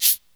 DrShake7.wav